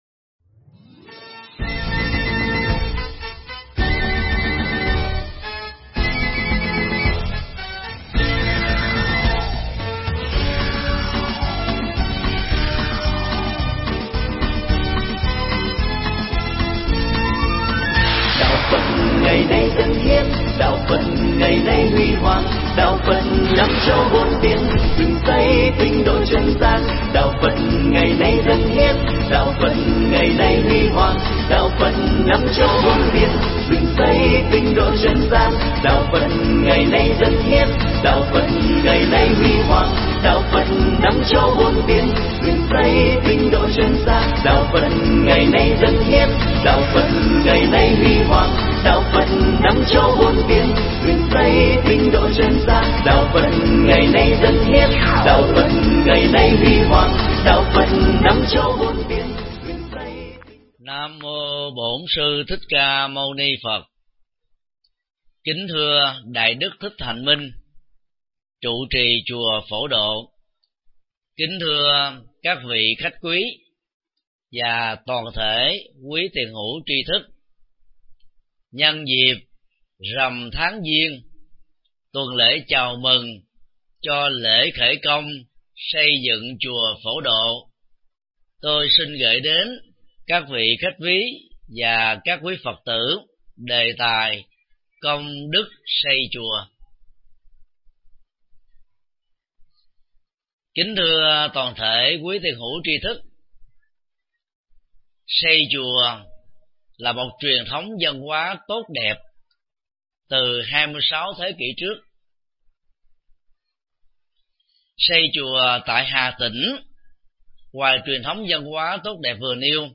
Nghe mp3 pháp thoại Công đức xây chùa do thầy Thích Nhật Từ Giảng tại chùa Phổ Độ, Hà Tĩnh, ngày 24 tháng 02 năm 2013